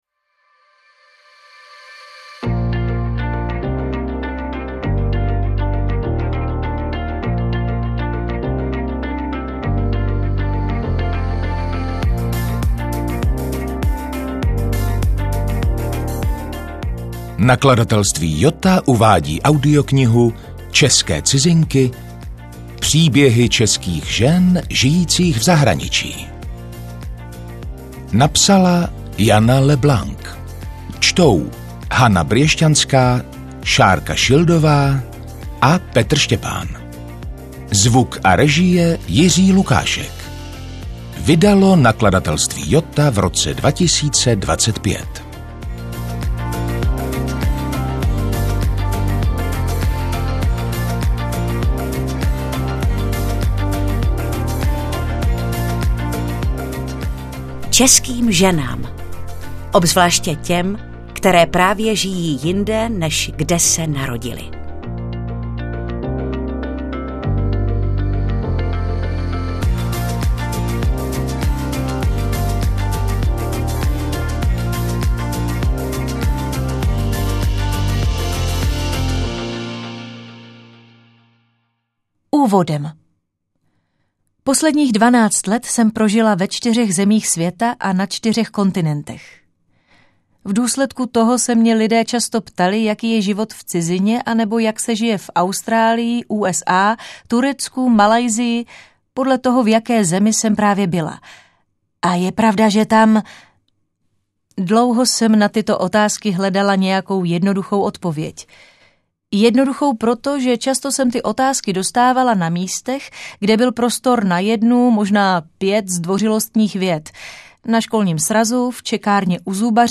AudioKniha ke stažení, 23 x mp3, délka 14 hod. 53 min., velikost 816,8 MB, česky